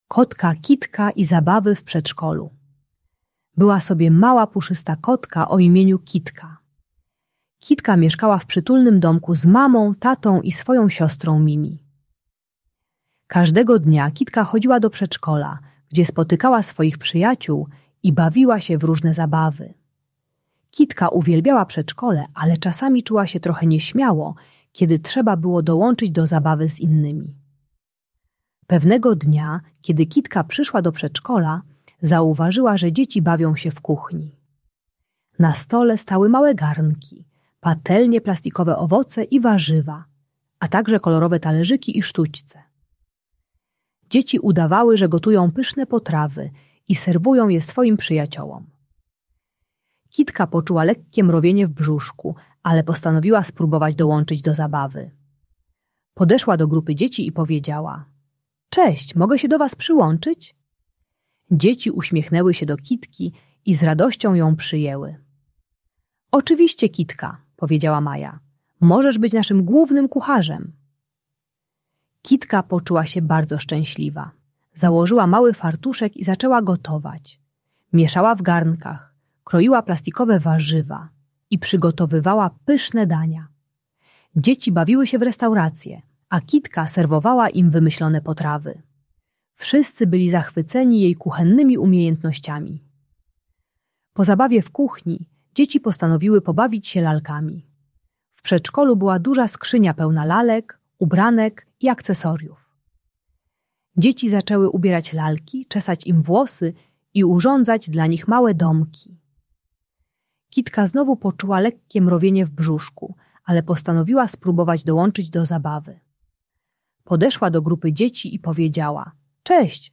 Kotka Kitka i Zabawy w Przedszkolu - Lęk wycofanie | Audiobajka